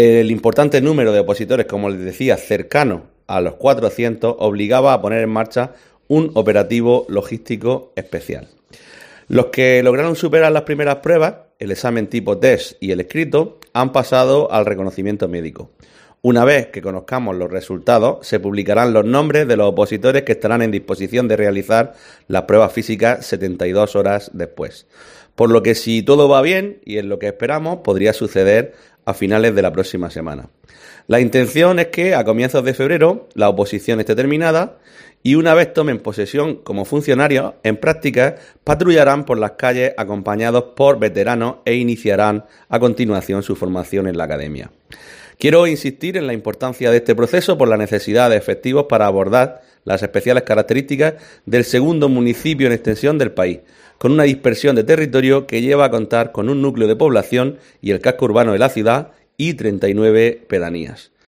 Juan Miguel Bayonas, edil Seguridad Ciudadana de Ayuntamiento de Lorca